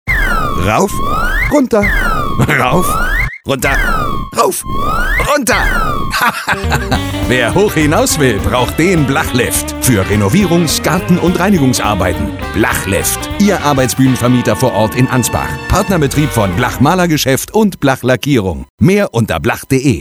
BLACHLIFT Radiowerbespots